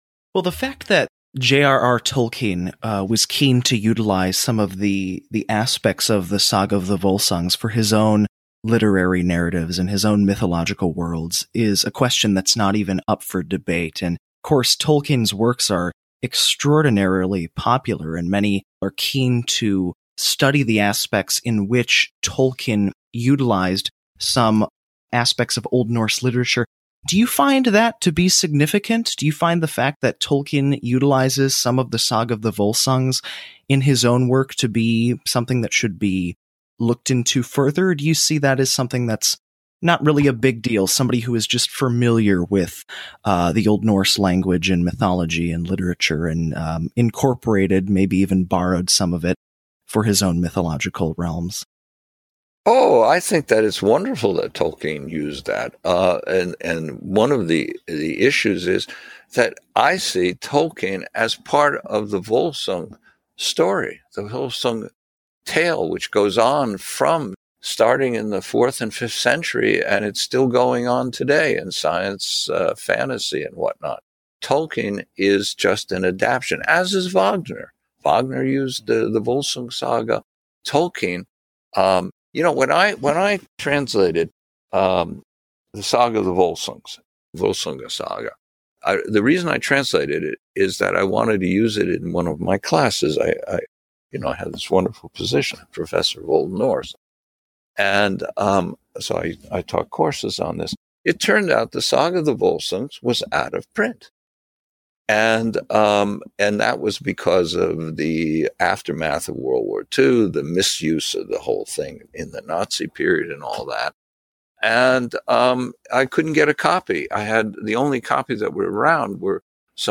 We’ve collected some of the best parts of their conversation here, but you can listen to the full podcast here.